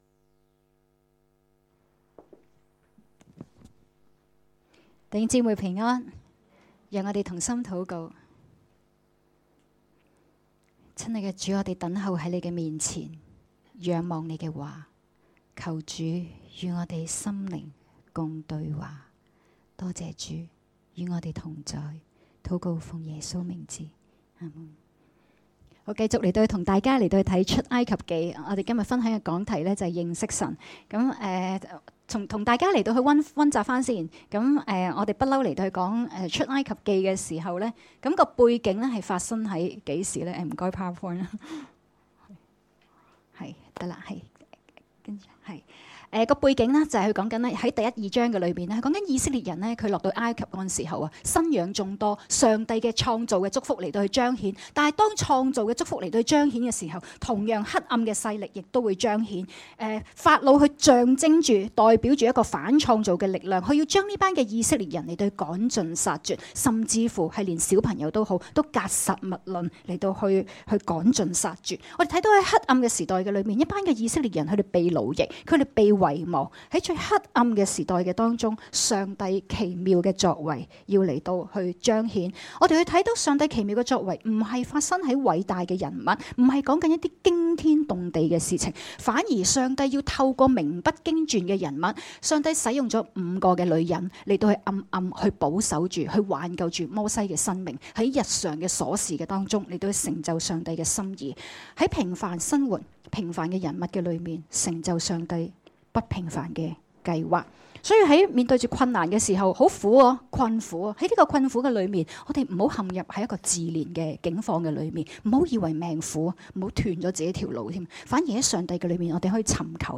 2017年1月14日及15日崇拜講道